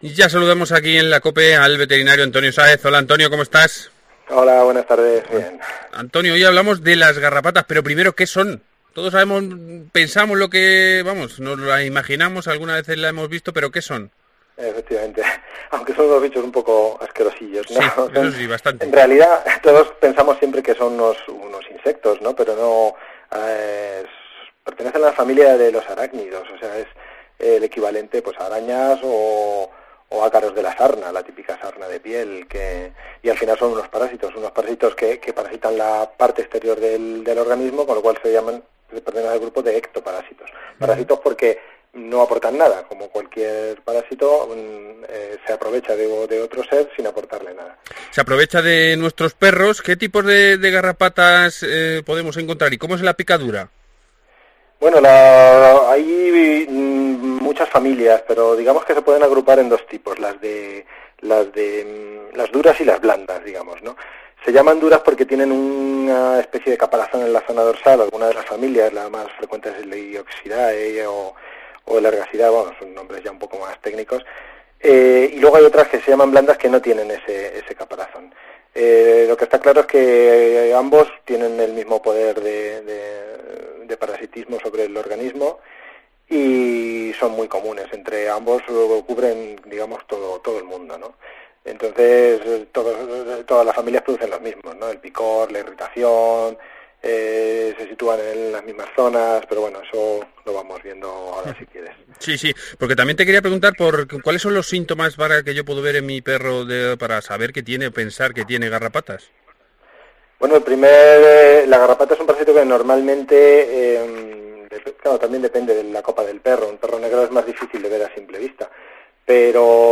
Hoy hablamos con el veterinario